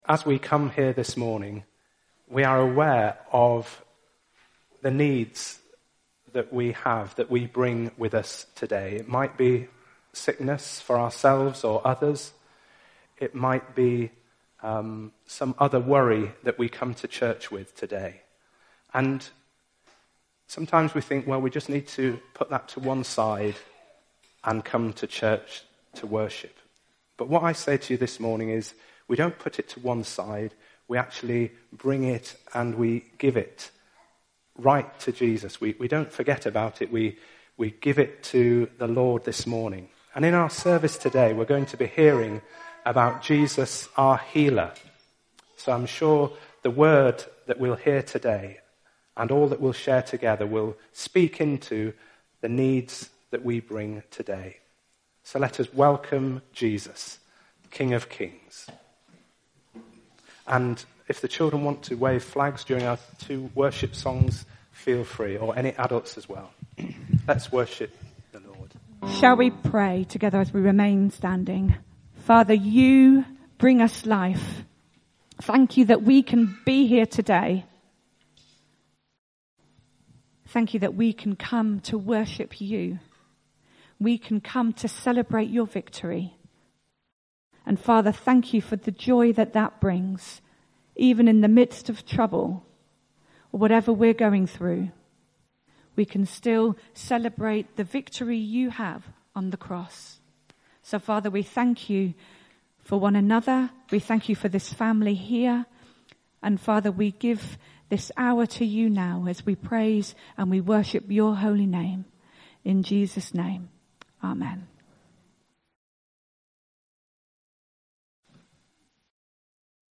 There is a dialogue and then the reading is presented as a dramatisation for three voices.